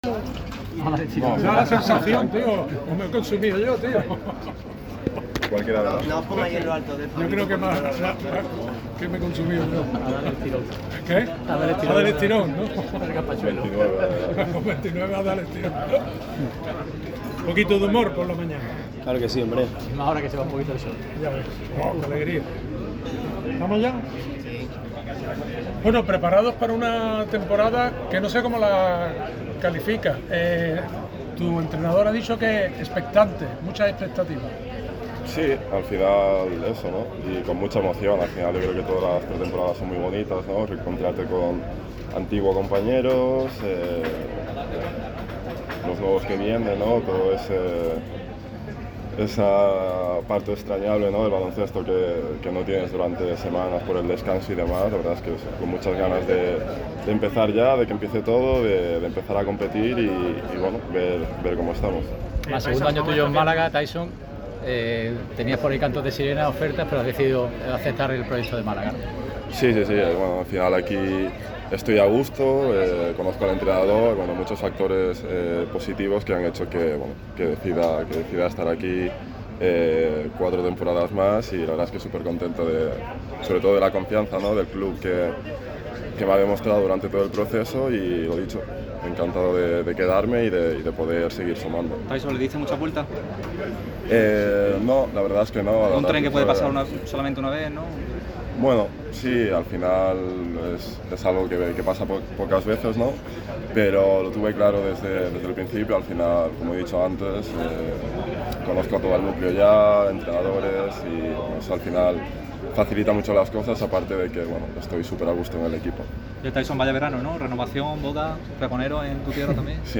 El desayuno informativo de la vuelta al trabajo del Unicaja ha servido para conocer sensaciones desde el interior del equipo malagueño.
El hispanodominicano del conjunto de Ibon Navarro se ha puesto ante los micrófonos. Además de sobre la temporada y los fichajes, Tyson Pérez se ha pronunciado sobre su renovación: “Lo tuve claro desde el principio”.